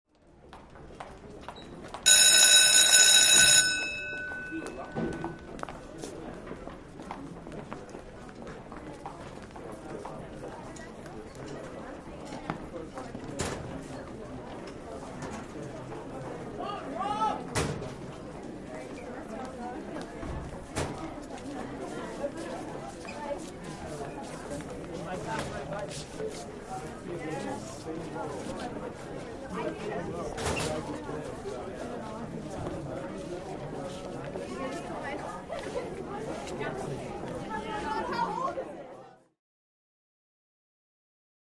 High School Hallway Ambience, Bell Ring, Footsteps., and Teen Walla.